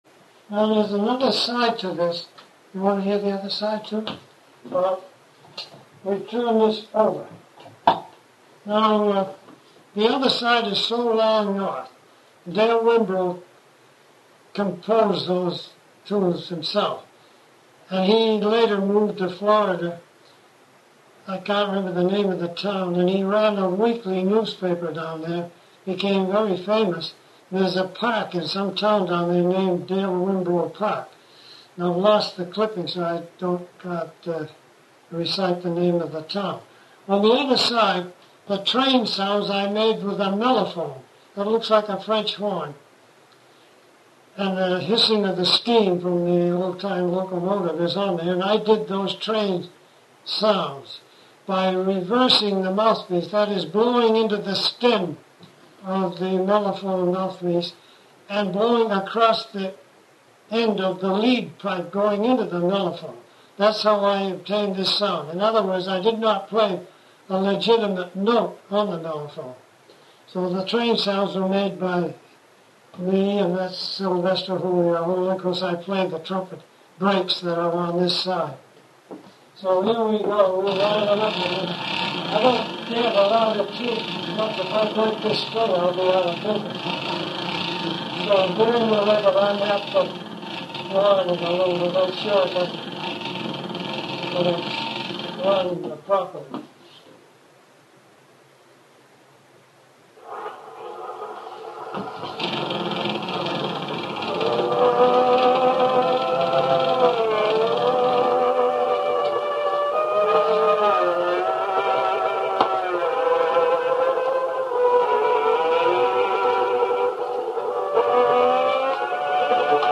talks about and plays on his Victrola
with a backing band that features trumpet solos and breaks